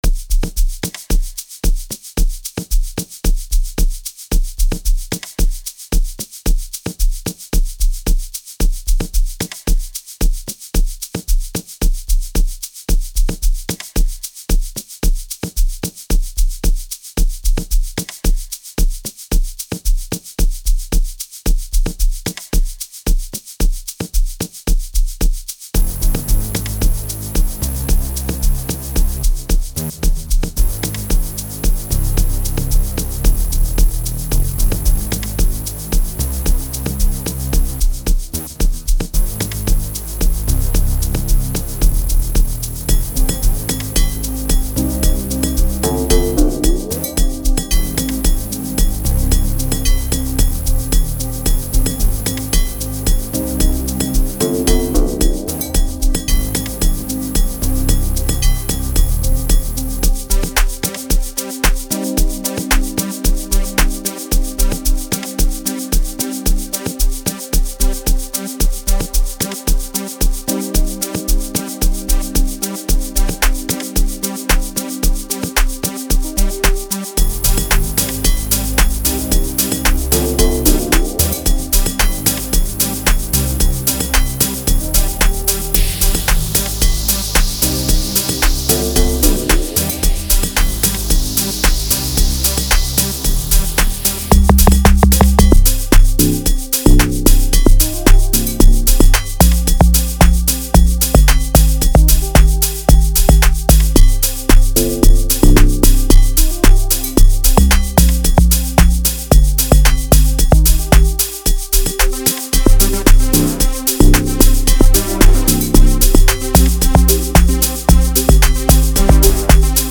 05:42 Genre : Amapiano Size